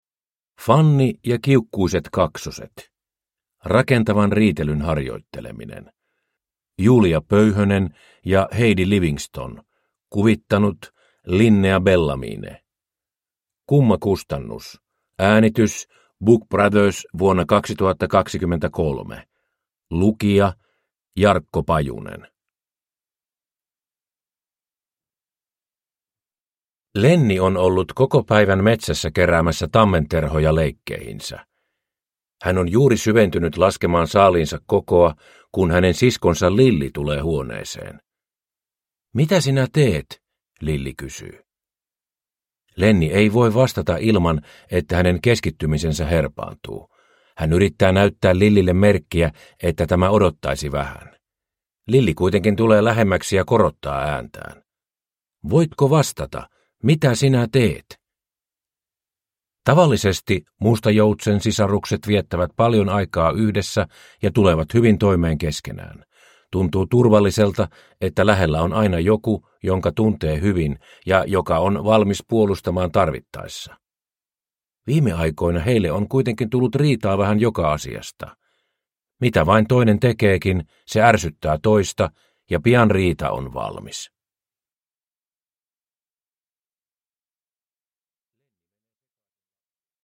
Fanni ja kiukkuiset kaksoset – Ljudbok – Laddas ner